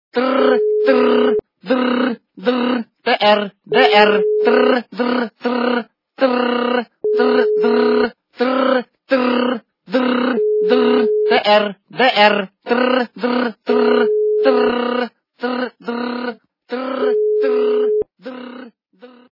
» Звуки » Смешные » Имитация старого звонка - Трррр-Трррр
При прослушивании Имитация старого звонка - Трррр-Трррр качество понижено и присутствуют гудки.